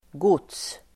Uttal: [got:s]